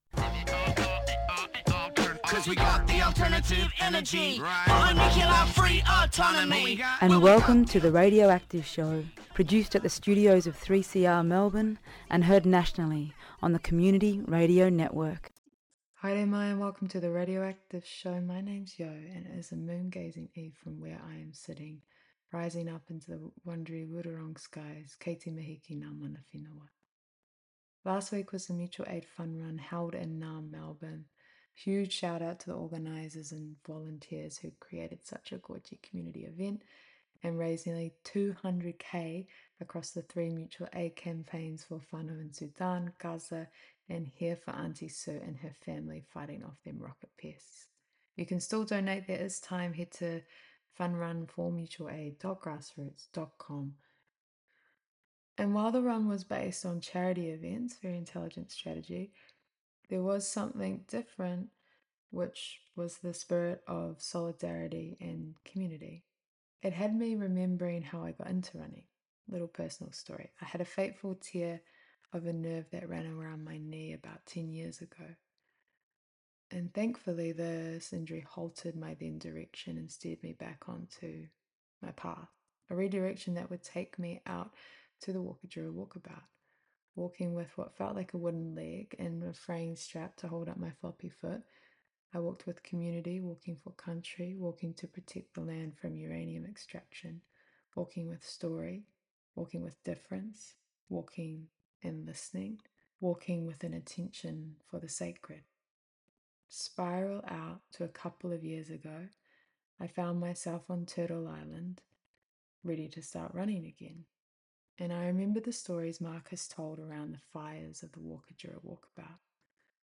Part One of a three part interview series.